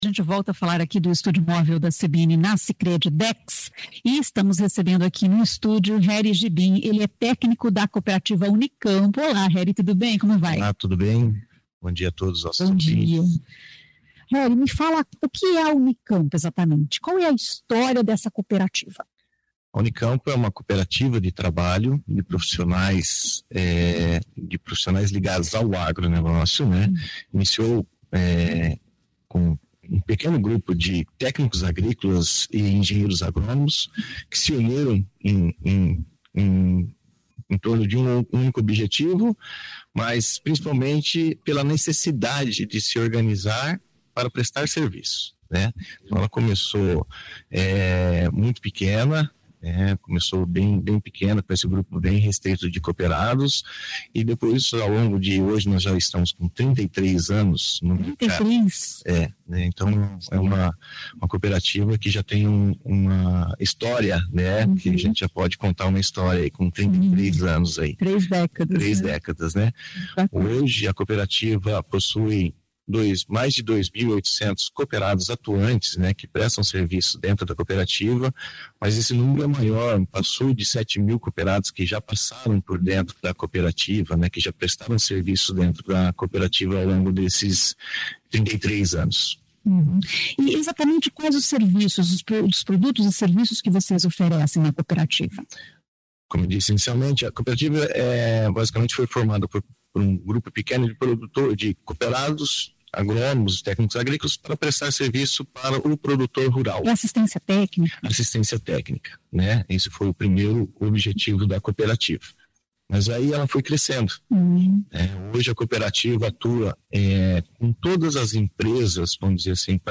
A entrevista foi realizada no Estúdio Móvel CBN, instalado na sede da Sicredi Dexis, em comemoração ao Dia Internacional do Cooperativismo, celebrado nesta quinta-feira (3).